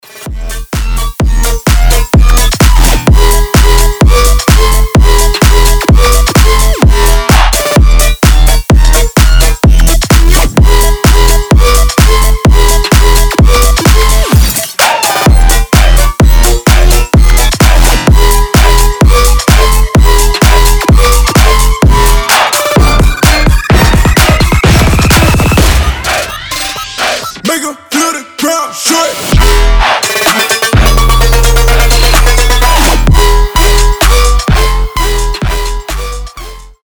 bass house , мощные басы